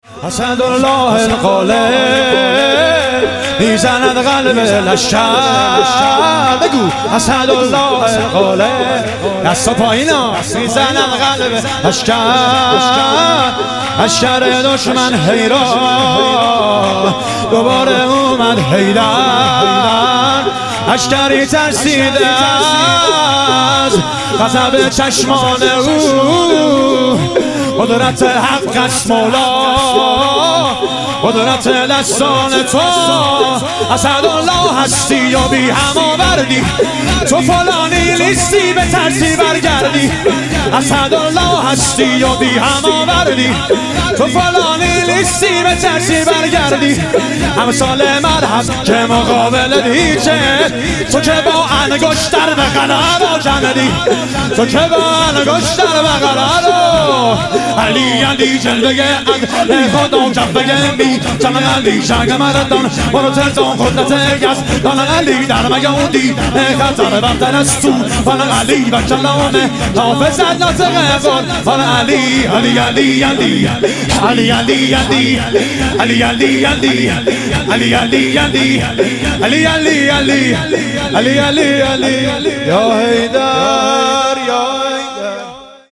عید سعید غدیر خم - شور